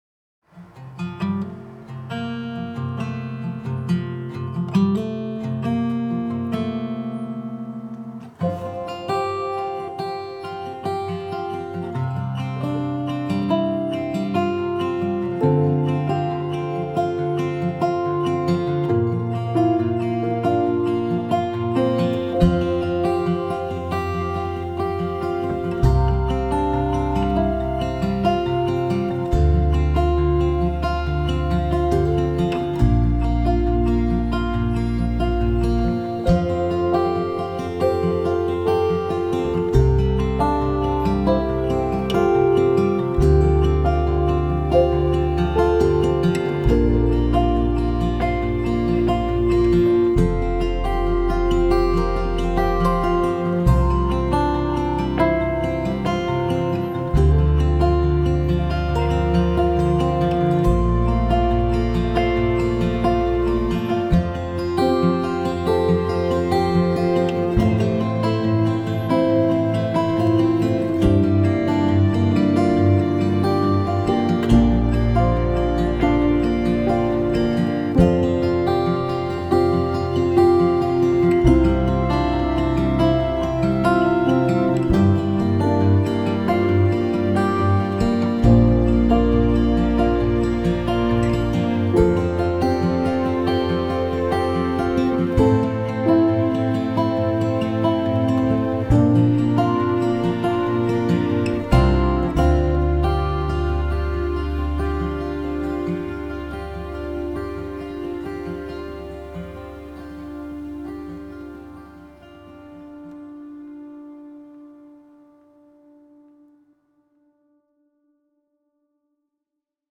très jolie partition intimiste